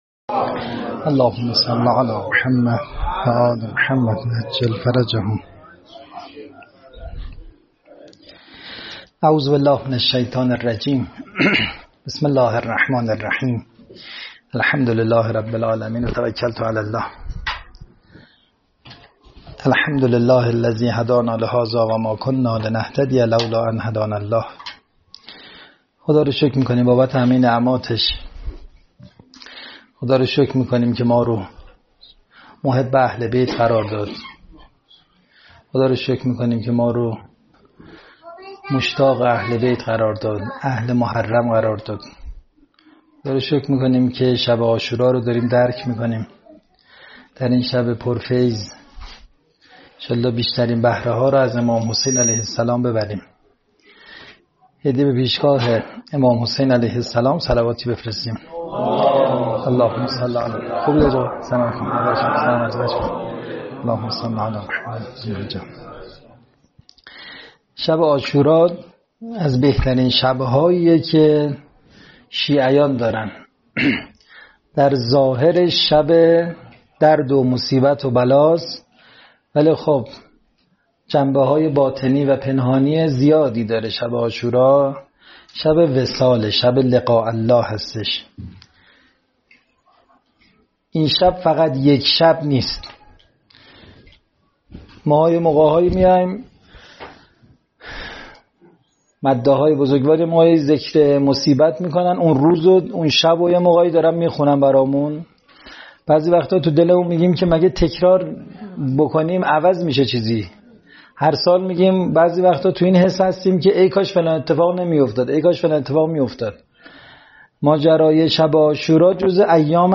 مع الحسین علیه السلام سخنرانی